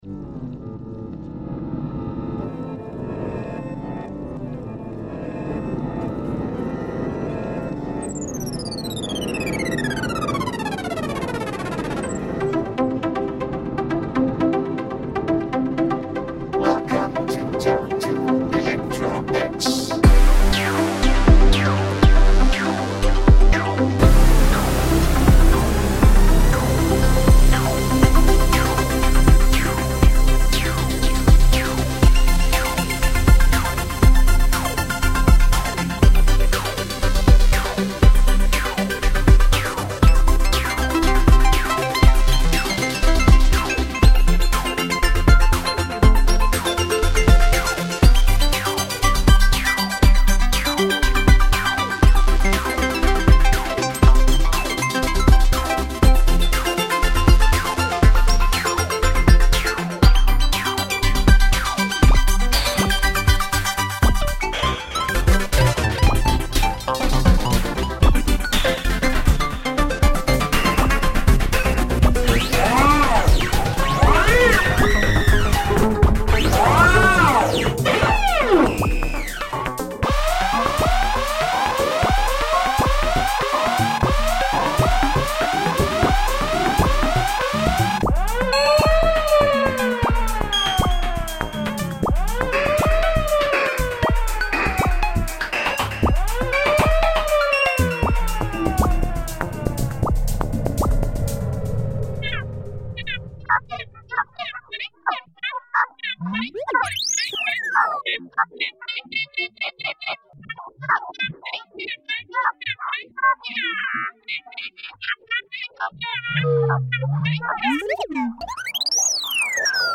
Hé non celui-là c'est le mien, avec les robots qui se chamaillent et qui tapent sur les humains.
WELCOME : est plus futuriste dans ses sonorités ça fonctionne super bien jusqu'à un peu après le milieu, après l'alarme atomique le freestyle avec les bruitages aurait pu être sympa si ça avait été traité avec du rythme ou de la musicalité, voir la zapper pour enchaîner sur la toute dernière partie.